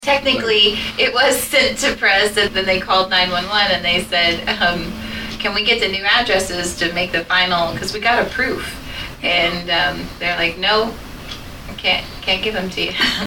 Saline County Treasurer Marty Smith asked county commissioners for an update on the status of the plat books during the commission meeting on Thursday, January 2.
Gooden replied: